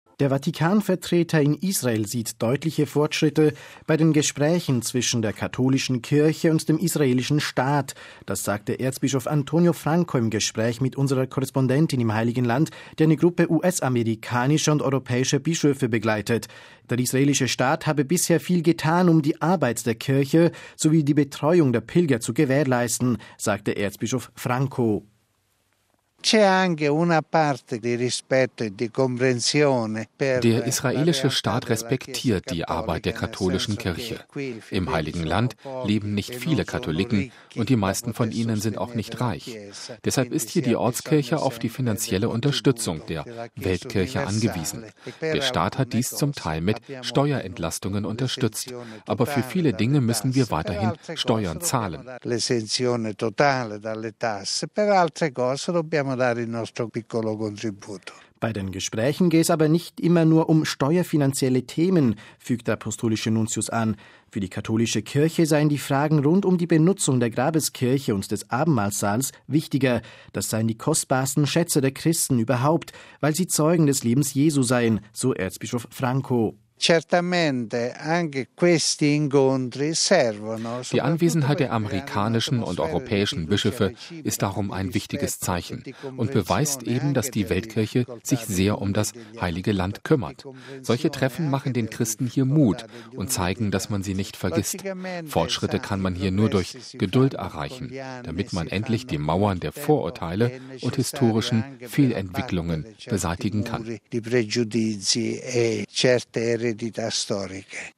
Das sagte Erzbischof Antonio Franco im Gespräch mit unserer Korrespondentin im Heiligen Land, die eine Gruppe us-amerikanischer und europäischer Bischöfe begleitet. Der israelische Staat habe bisher viel getan, um die Arbeit der Kirche sowie die Betreuung der Pilger zu gewährleisten, sagte Erzbischof Franco.